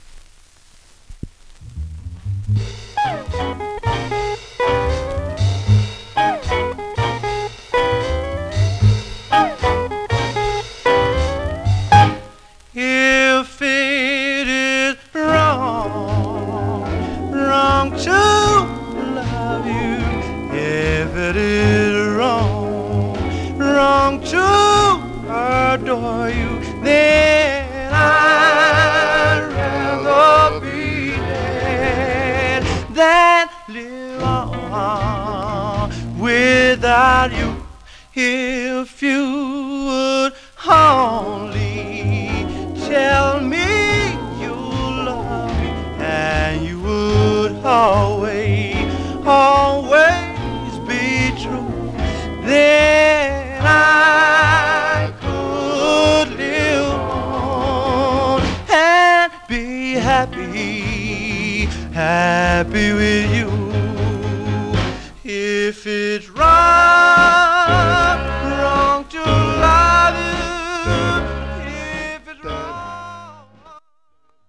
Stereo/mono Mono
Male Black Group Condition